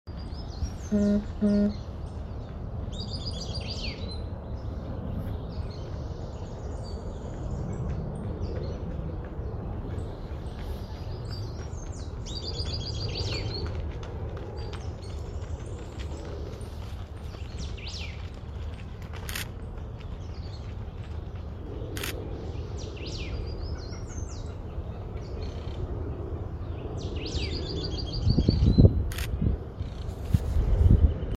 Piojito Común (Serpophaga subcristata)
Localidad o área protegida: Reserva Ecológica Costanera Sur (RECS)
Condición: Silvestre
Certeza: Observada, Vocalización Grabada
piojito-silbon.mp3